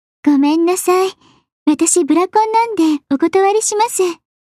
サンプルボイス（クリックすると再生されます）